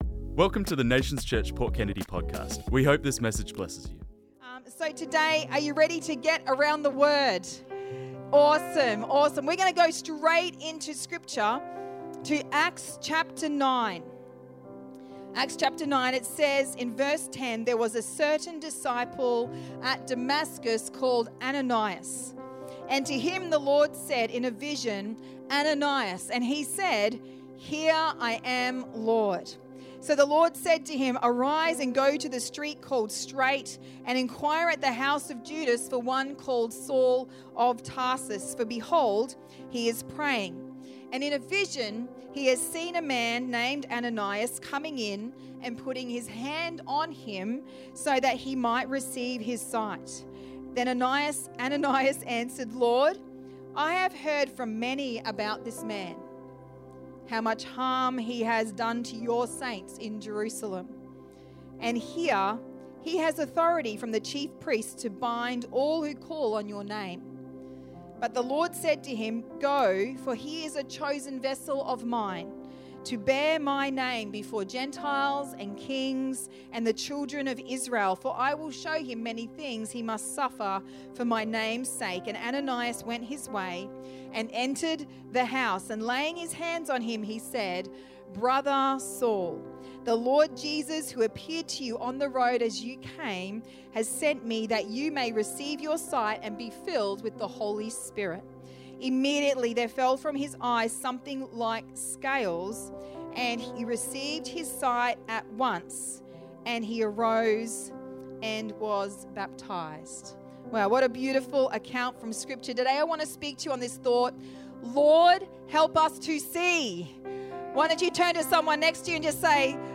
This message was preached on Sunday the 9th November 2025